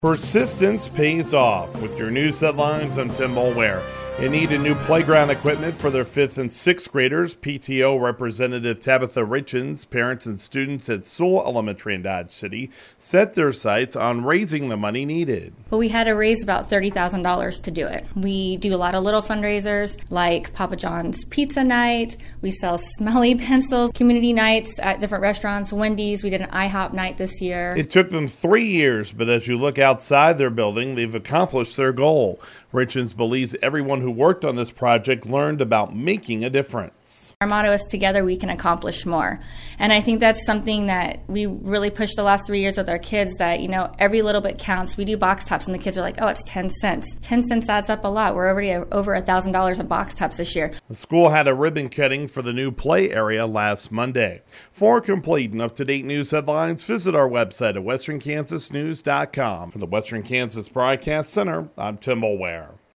*On-air story*